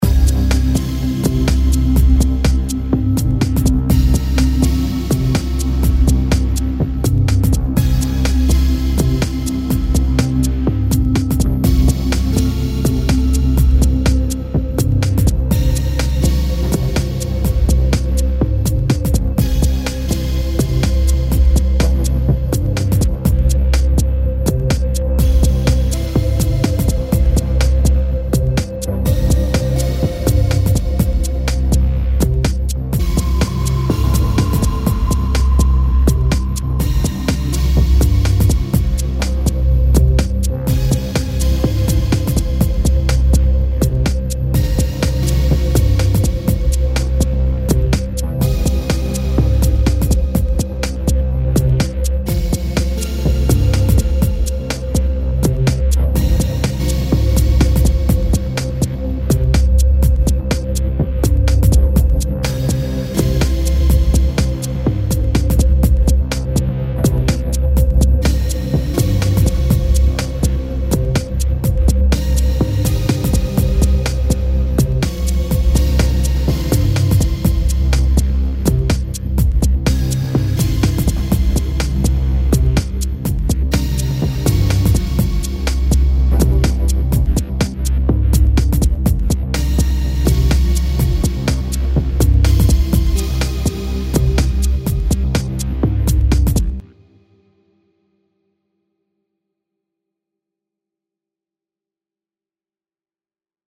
Largo [0-10] - - - -